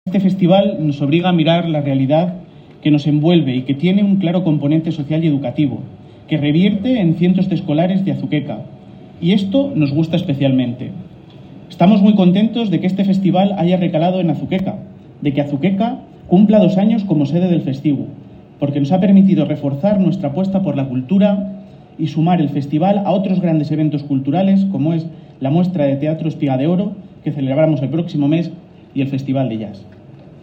Declaraciones del alcalde, Miguel Óscar Aparicio 1 Declaraciones del alcalde, Miguel Óscar Aparicio 2
El alcalde, Miguel Óscar Aparicio, ha señalado en el discurso inaugural que el festival de cine ha permitido “reforzar nuestra apuesta cultural” y ha subrayado su componente “social y educativo”